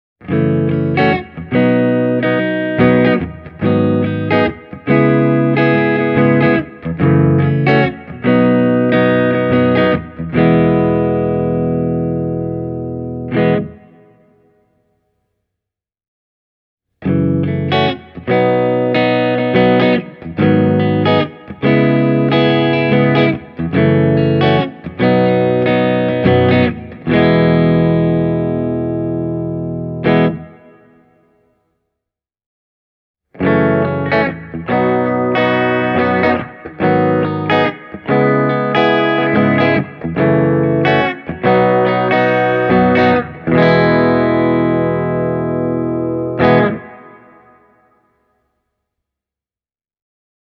Testasin Carvin Legacy 3 -nupin vaahterakaulaisella Fender Stratocasterilla ja Hamer USA Studio Custom -kitaralla Zilla Cabsin 2 x 12” -kaapin kautta, ja tulokset puhuvat hyvin selkeää kieltä – tässä on kyseessä pro-luokan vahvistin.
Legacyn puhdas kanava on erittäin lähellä omaa ihanne-clean-soundia – sointi on lämmin, avoin ja putipuhdas.
Hamer Studio Custom – kanava 1